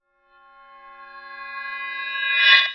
time_warp_reverse_high_03.wav